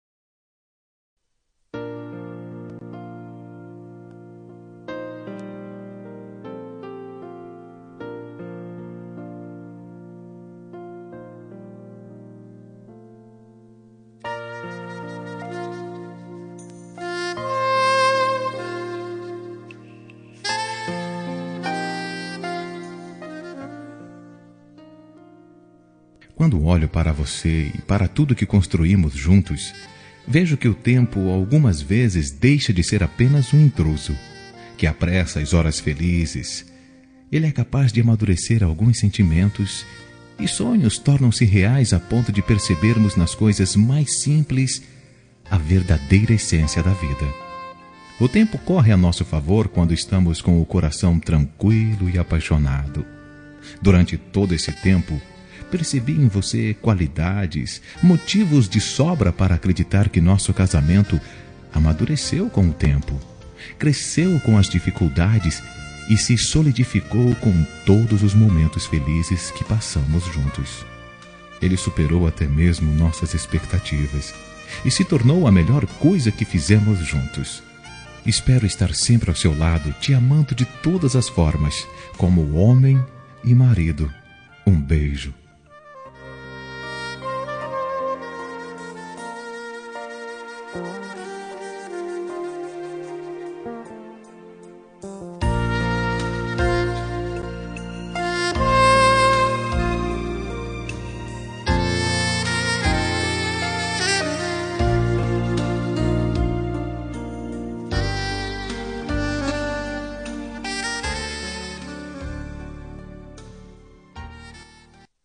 Telemensagem de Aniversário de Esposa – Voz Masculina – Cód: 1123